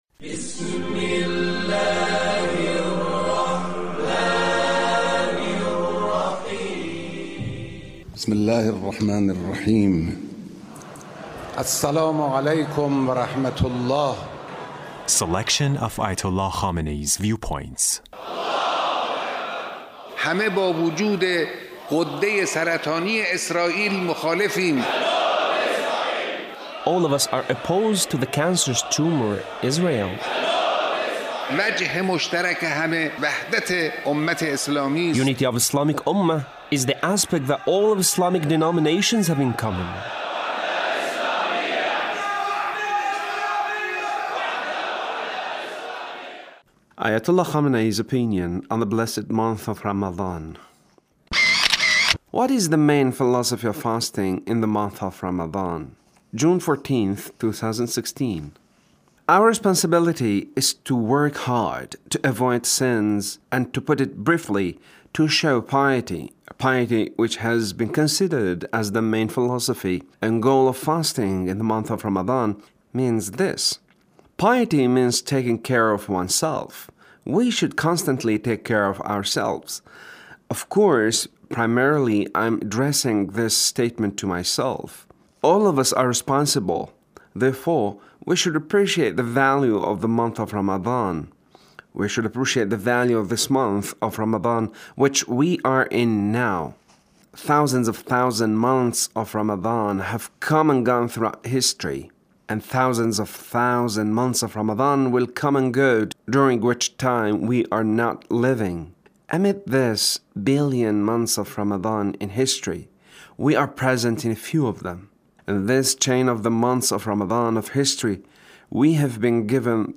Leader's speech (1354)